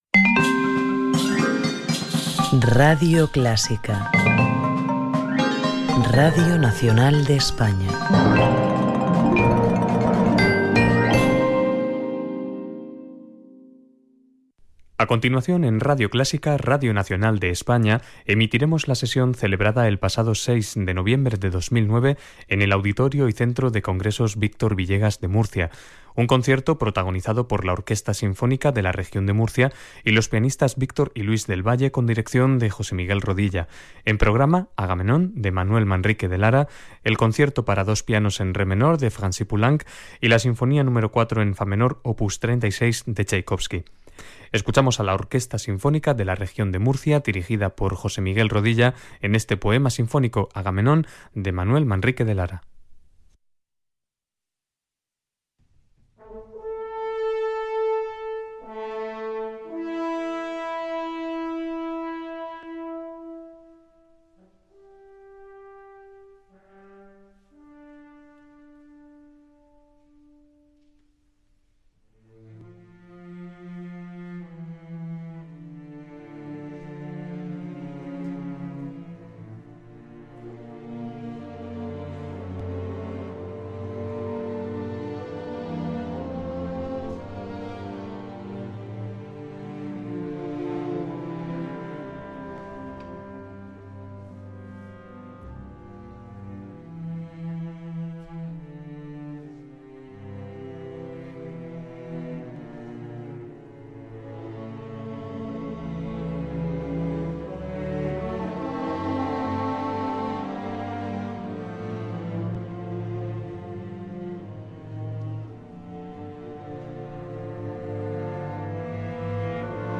Duo-pianos